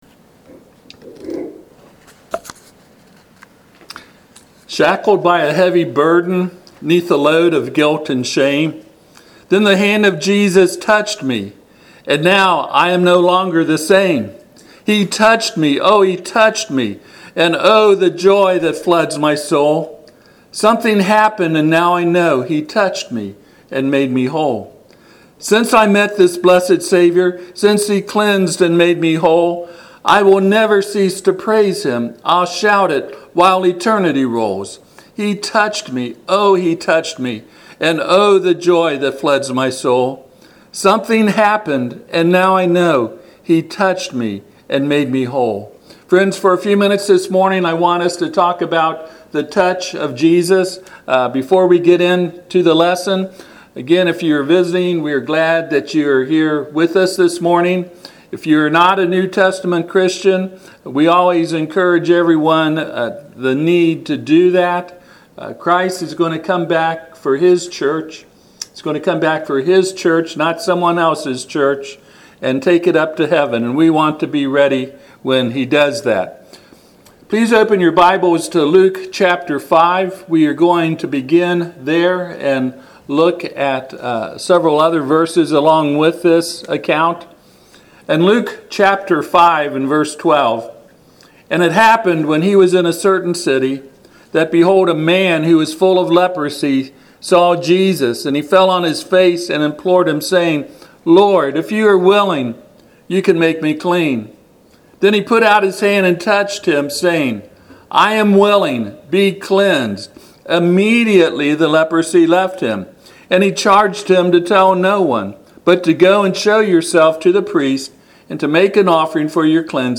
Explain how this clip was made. Passage: Luke 5:12-13 Service Type: Sunday AM